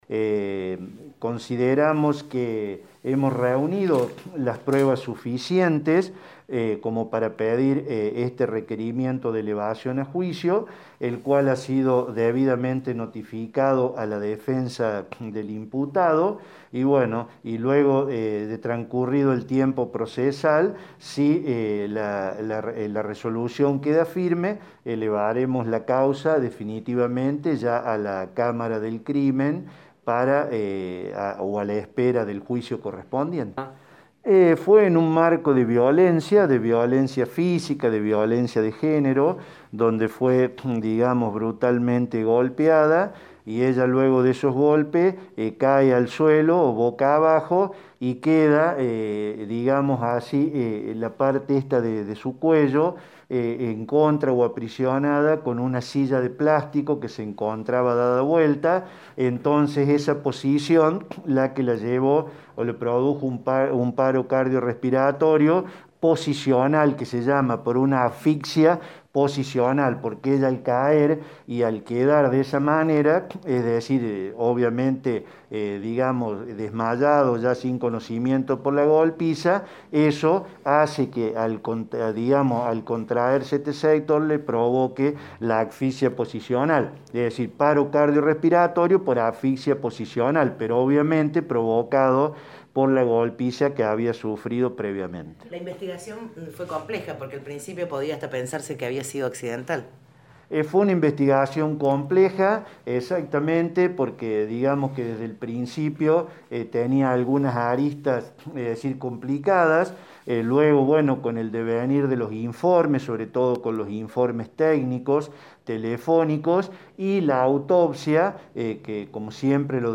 El funcionario Judicial habló con nuestro medio y se refirió a las pruebas del caso.